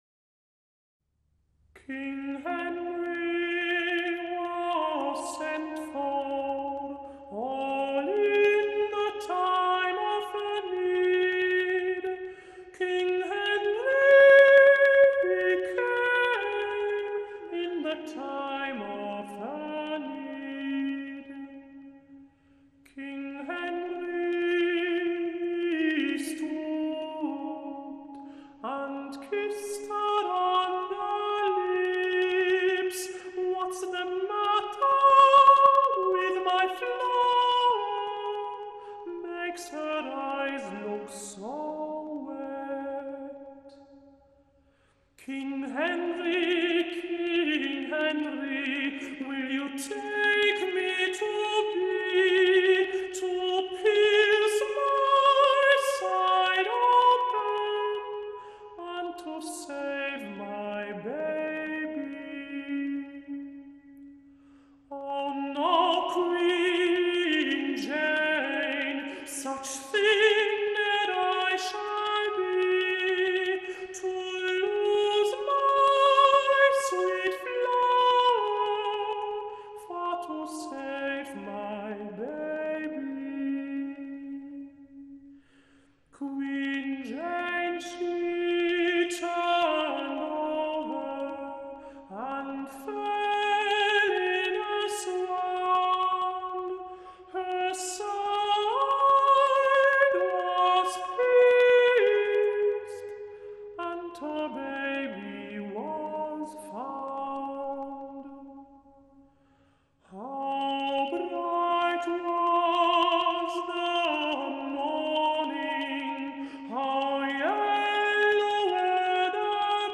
[分享]一首 德国假声男高音 （清唱）值得一听